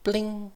snd_medal_pop.ogg